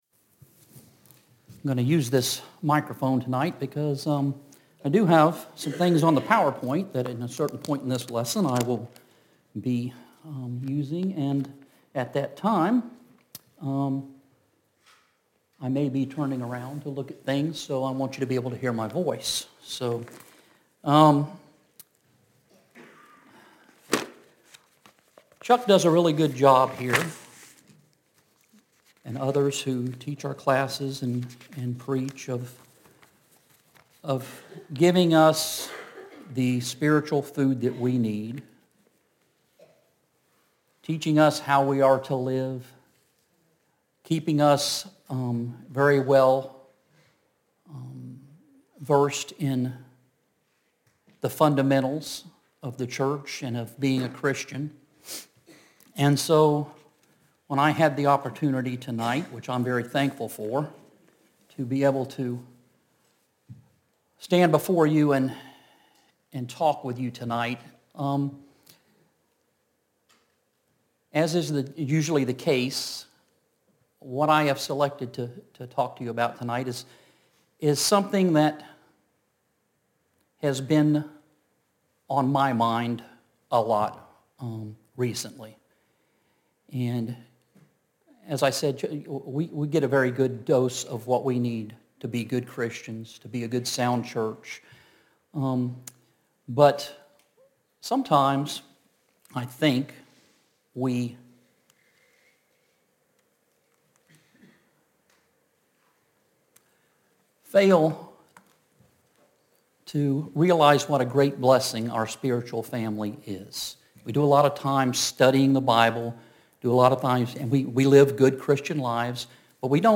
Sun PM Sermon – Our Spiritual Family